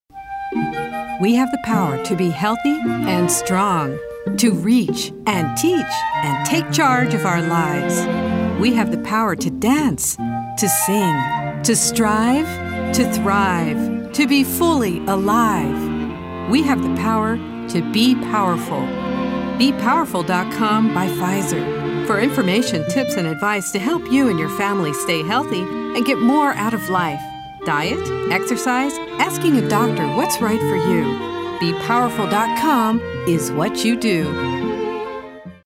a smooth and resonant voice
Health (empowering, uplifting, warm, nurturing)